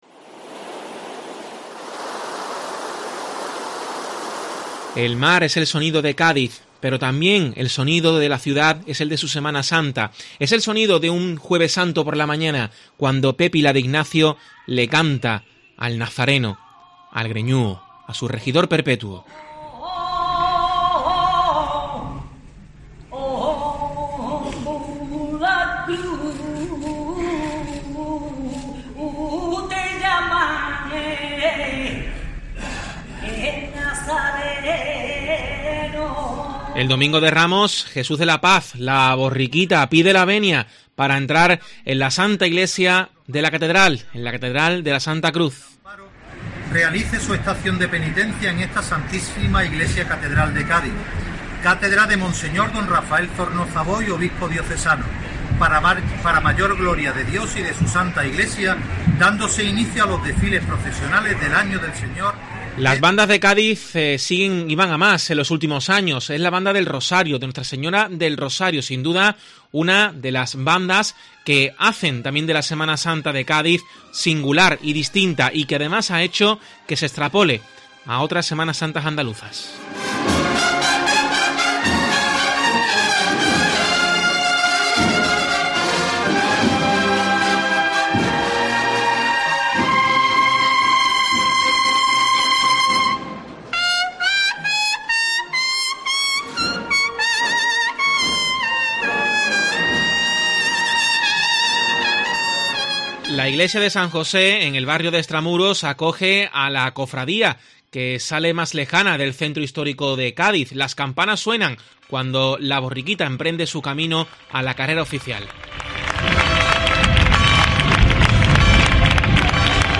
DESCARGA AQUÍ LOS SONIDOS IMPRESCINDIBLES DE LA SEMANA SANTA EN ALMERÍA
Entre los Sonidos podemos escuchar desde un tamboril rociero a un coro del Carnaval de Cádiz. Rezamos cantando por bulerías, nos sobrecogemos con el Himno de la Legión o nos acercamos a lo más alto escuchando las voces celestiales de las Hermanas de la Cruz.
Oración en la calle.